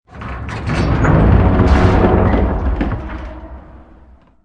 orbitgearretract.ogg